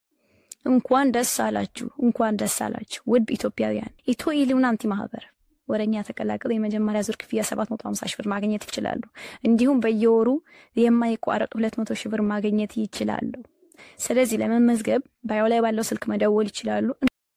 Ethiopian breaking news sound effects free download